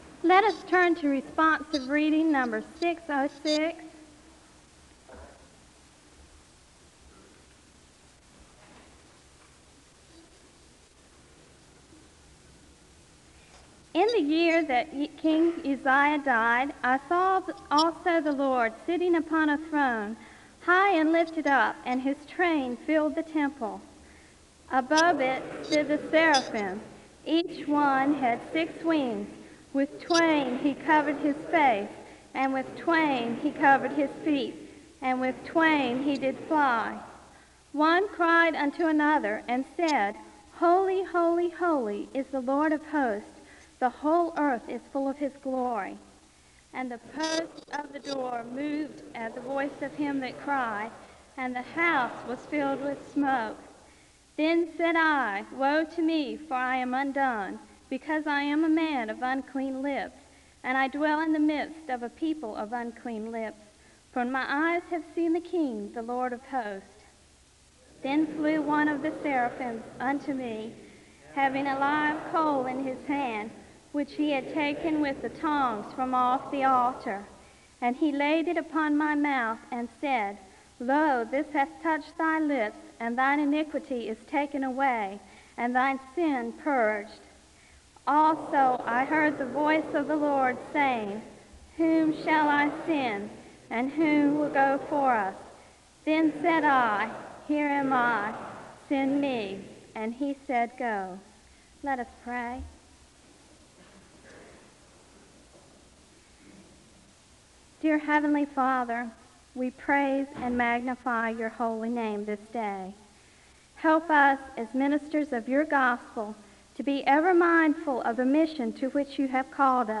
SEBTS Chapel
The service begins with a responsive reading from Isaiah 6 and a word of prayer (00:00-02:36). The choir sings a song of worship (02:37-07:05).
The service ends with a word of prayer (23:49-25:50).